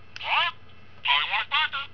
. . . and there's POLLY. Just wait 'til they find out she
TALKS.
wedpollysound.aif